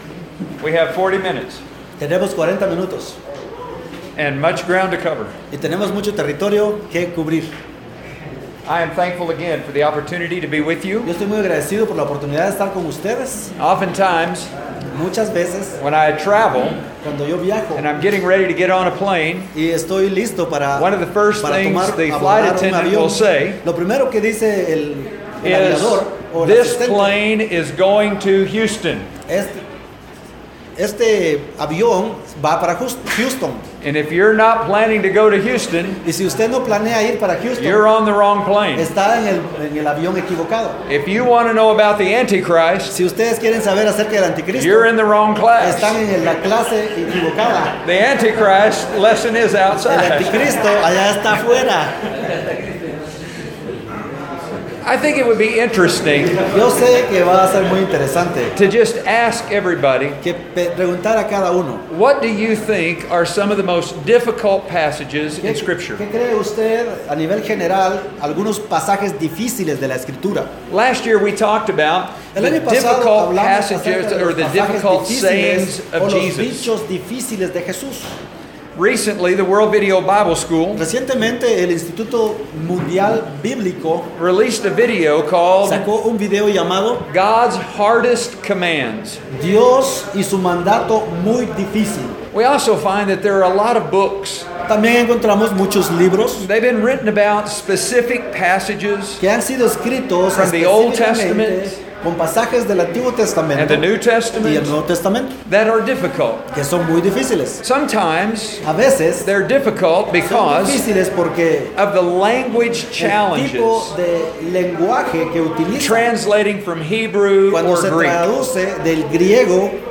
by admin | Apr 28, 2019 | ITL Lectureship 2019, Lectureships/Seminarios, Sermon